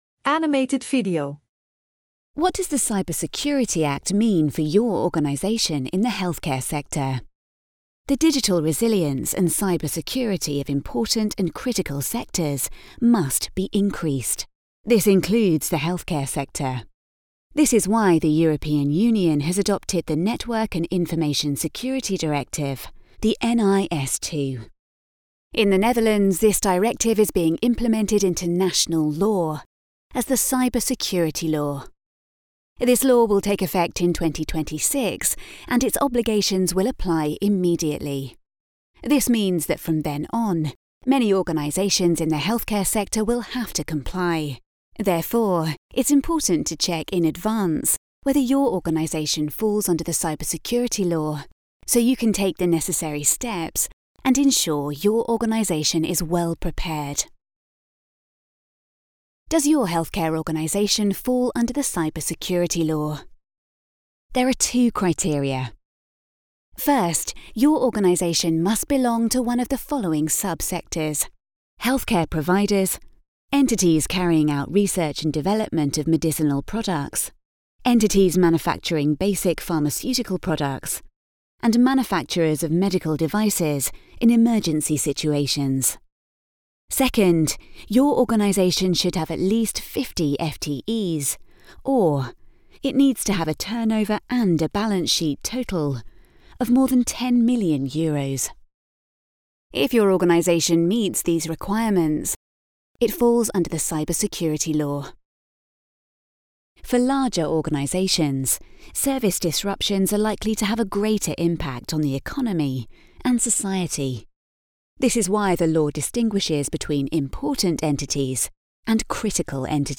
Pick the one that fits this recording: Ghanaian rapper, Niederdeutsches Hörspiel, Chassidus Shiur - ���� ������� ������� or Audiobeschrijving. Audiobeschrijving